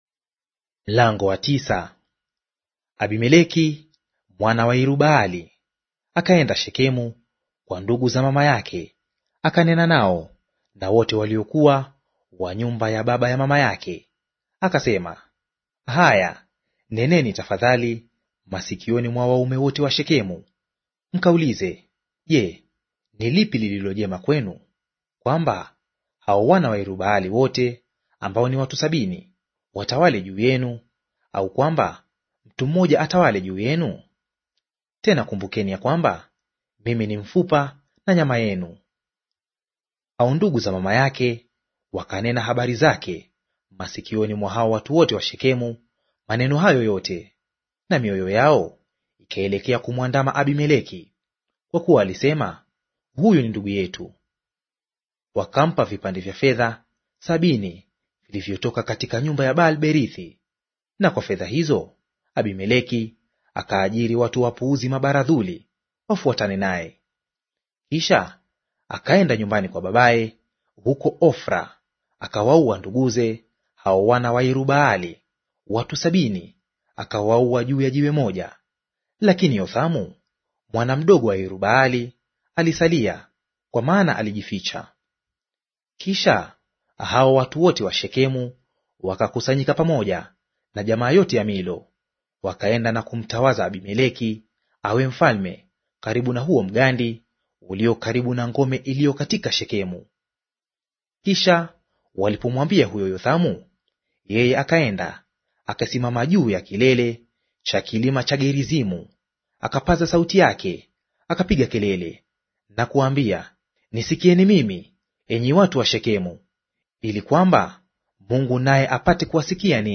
Audio reading of Waamuzi Chapter 9 in Swahili